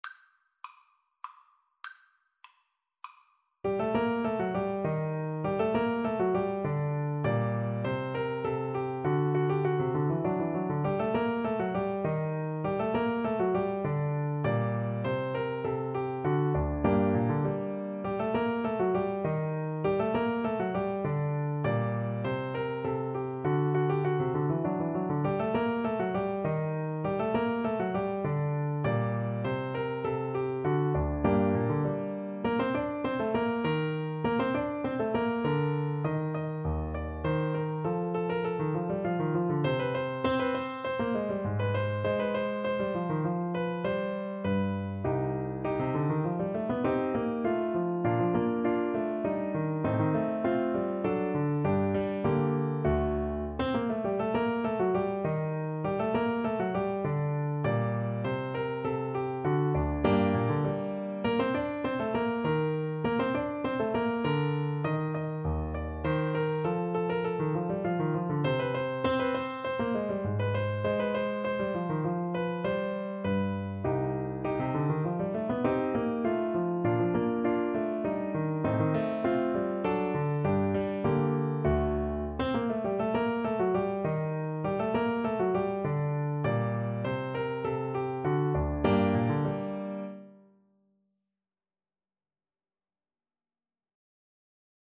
Allegretto = 100
Classical (View more Classical Clarinet Music)